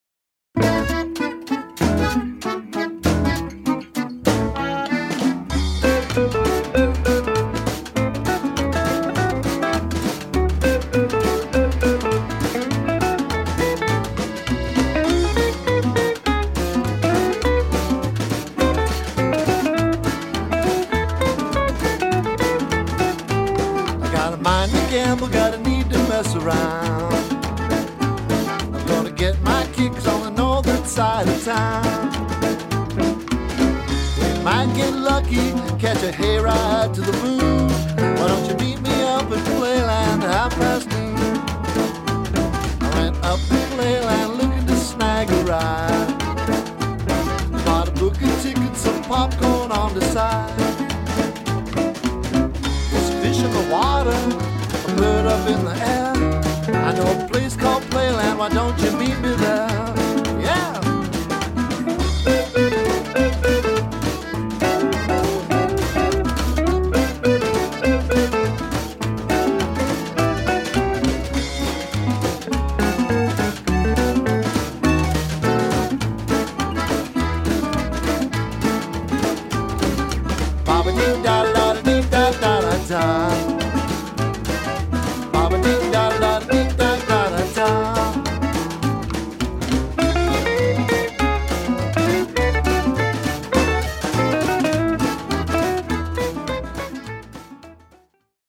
piano
bass
drums